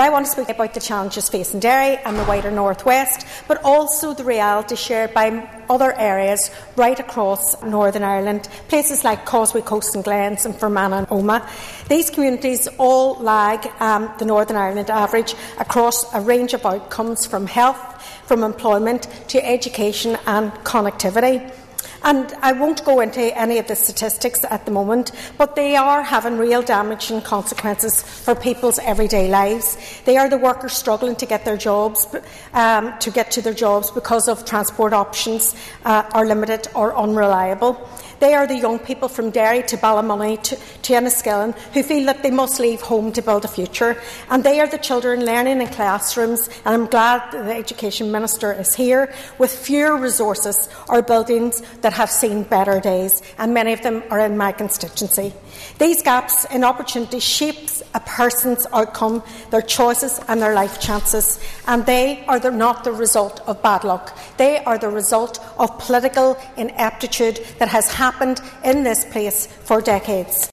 She told the Assembly there must be action to ensure regional balance is achieved……